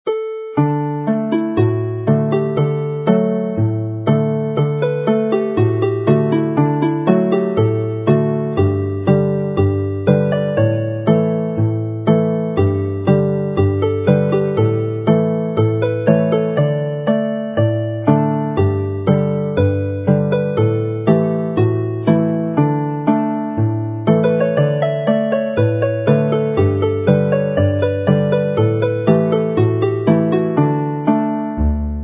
Triban syml yn D - cân ac amrywiad ar y ffidil
Triban in D with fiddle variation in repeat